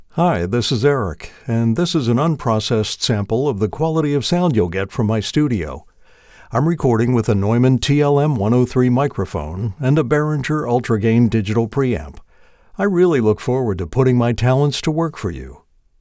Male
Adult (30-50), Older Sound (50+)
My voice ranges from strong and convincing to warm, friendly and conversational ... all with expressive delivery.
Studio Quality Sample
All our voice actors have professional broadcast quality recording studios.